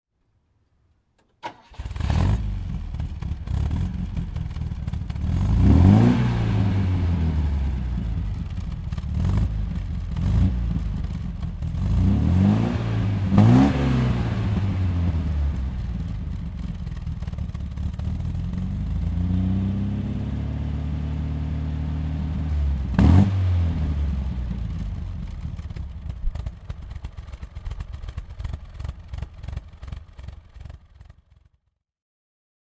Motorsounds und Tonaufnahmen zu MG Fahrzeugen (zufällige Auswahl)
MG B GT (1970) - Starten und Leerlauf
MG_B_GT_4_Cyl_1970.mp3